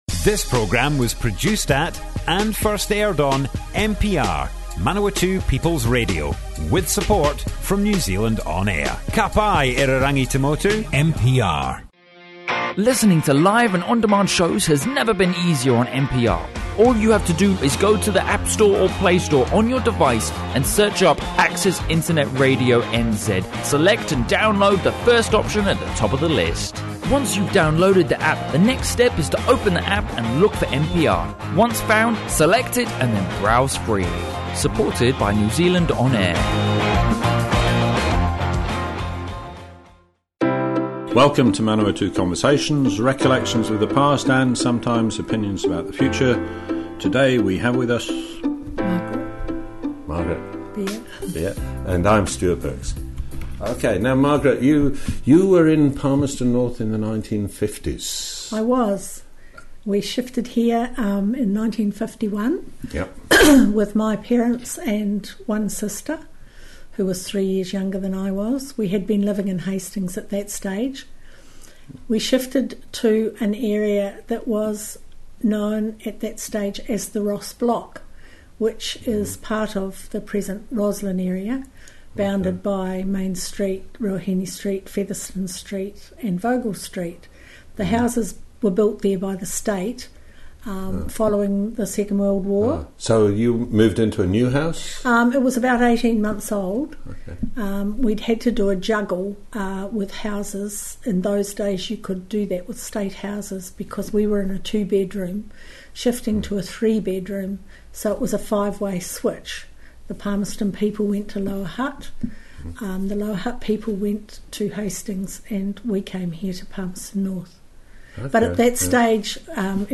Manawatu Conversations More Info → Description Broadcast on Manawatu People's Radio, 30 October 2018.
oral history